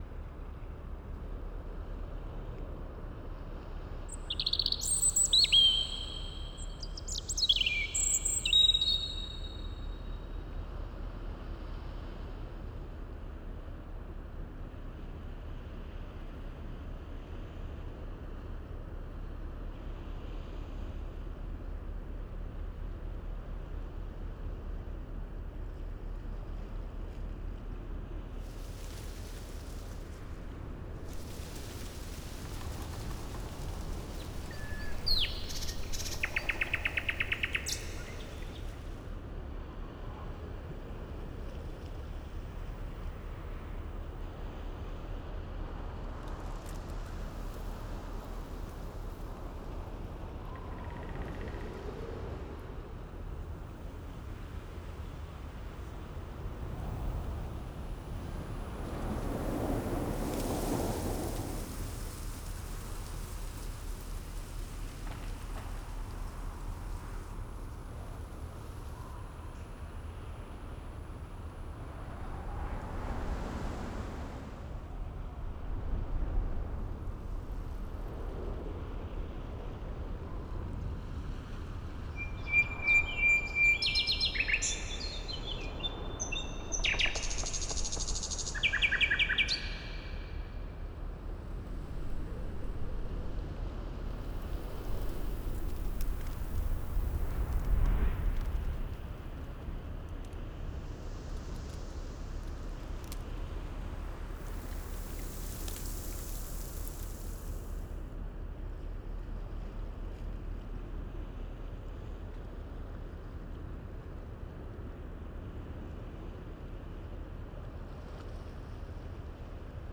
ForestAtmos_03.wav